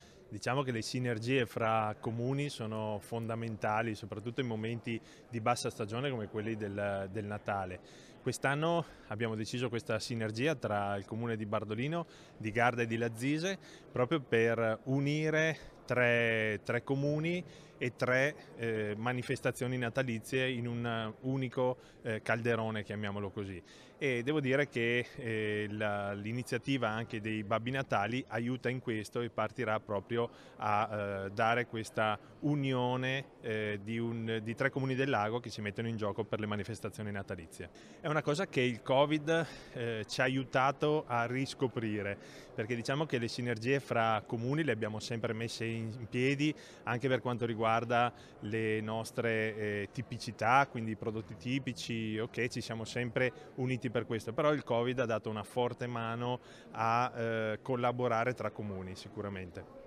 Lauro Sabaini – Sindaco di Bardolino: